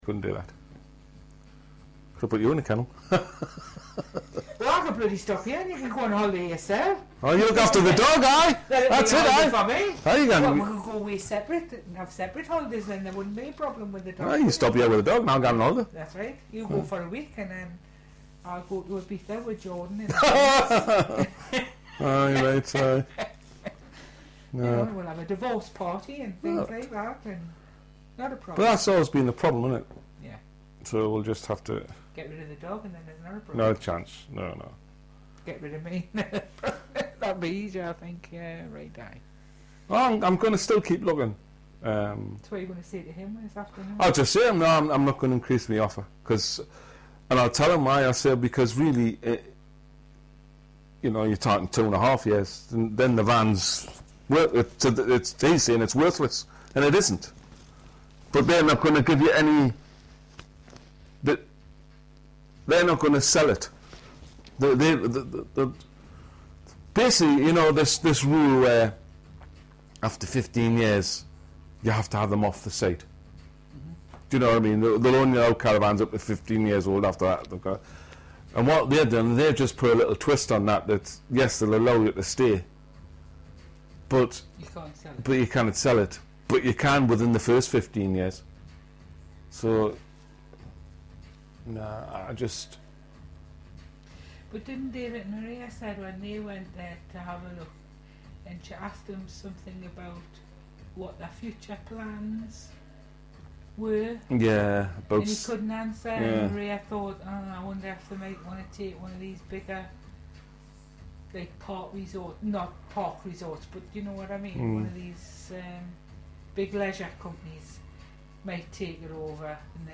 They are taken from the free conversation which was recorded as part of the fieldwork session. Informants were recorded in aged-matched pairs, as described above.
bullet Newcastle older speakers 2 [
ncl-older-2.mp3